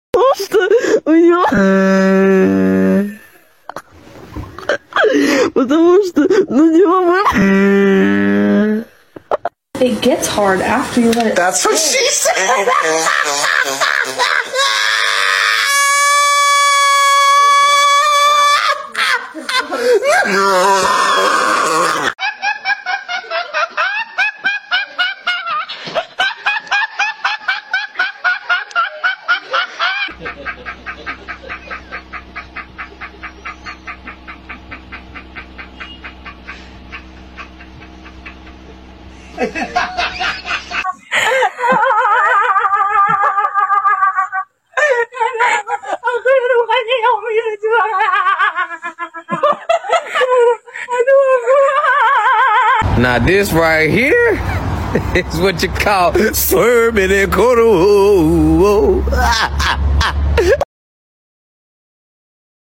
The funniest laughs I've heard sound effects free download